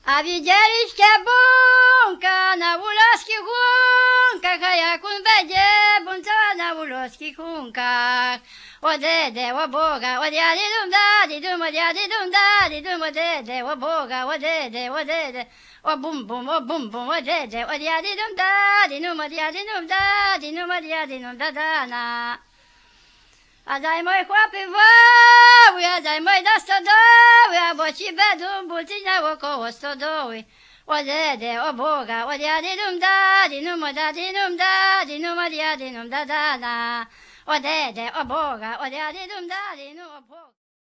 Badania terenowe
¶piew